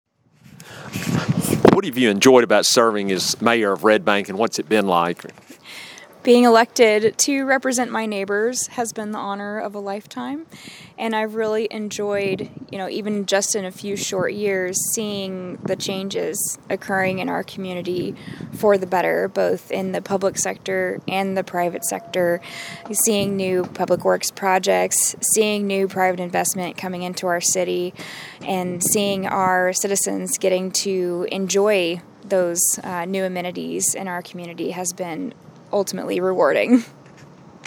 To listen to Mayor Hollie Berry discuss her enjoyment in serving Red Bank, Click